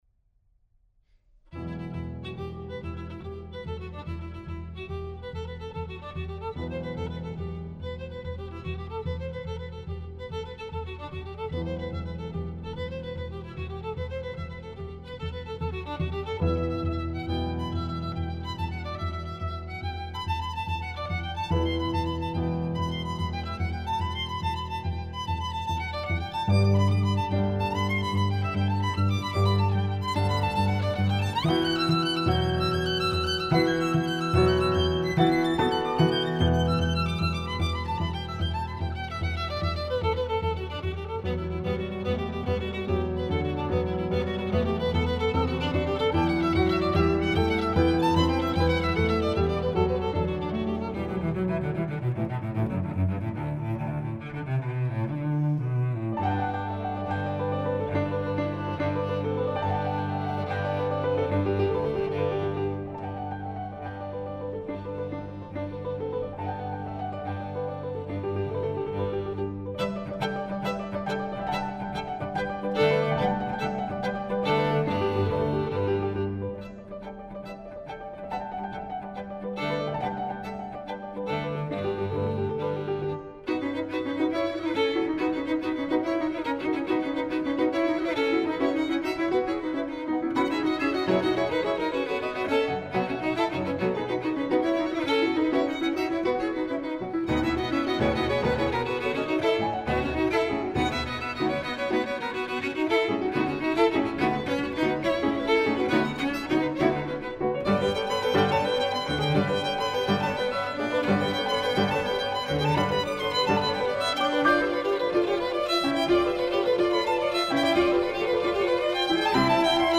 piano
violin
cello